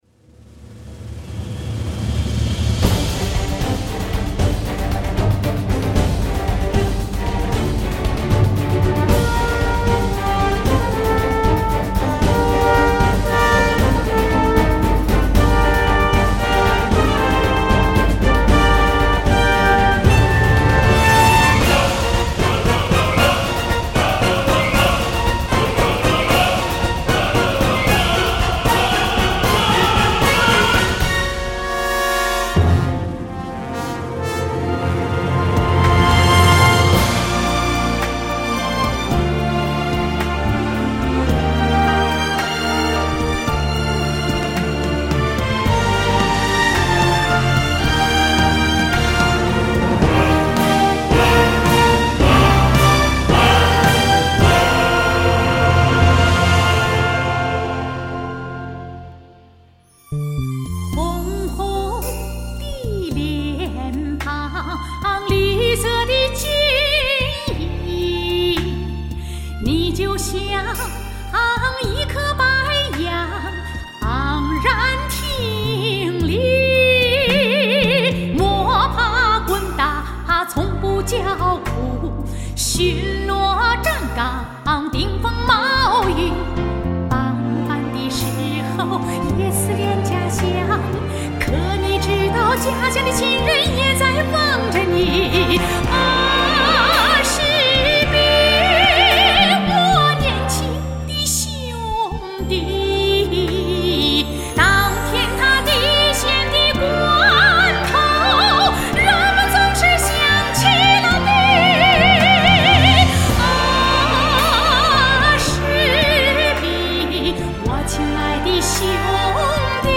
用音乐和情感吐字，她的演唱有着玉槌敲击金铃般的质感，雨滴芭蕉般的清丽。
合唱